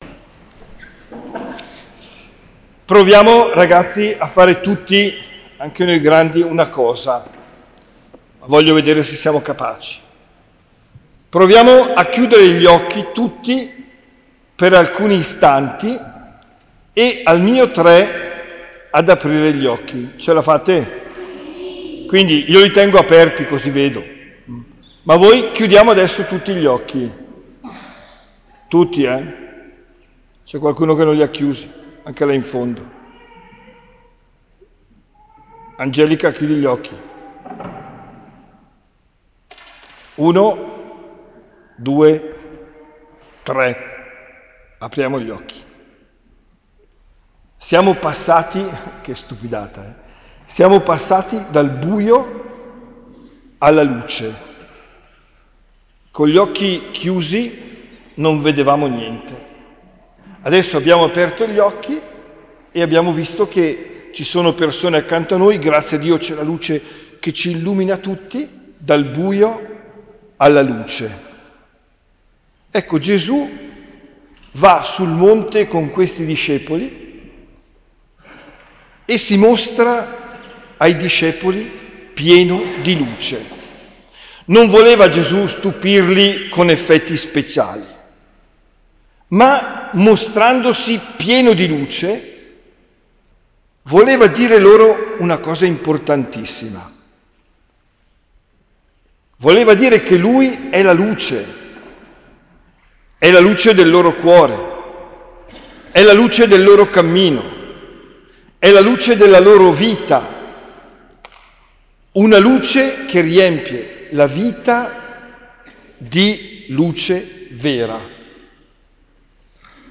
OMELIA DEL 05 MARZO 2023